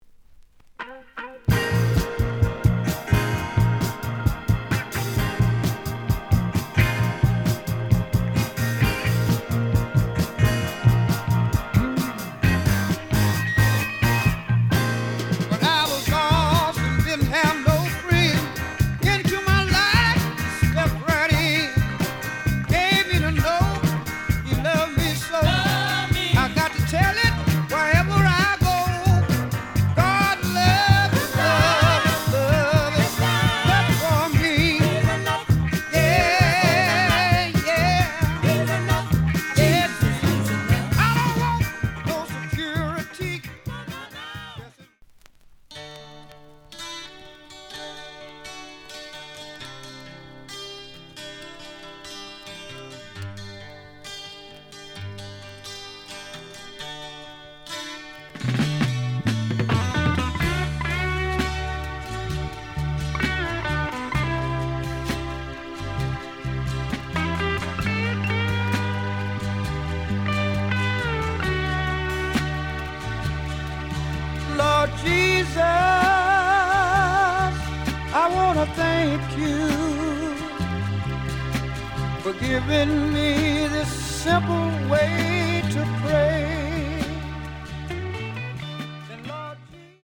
試聴は実際のレコードから録音しています。
The audio sample is recorded from the actual item.
●Genre: Soul, 70's Soul
Slight edge warp. But doesn't affect playing. Plays good.)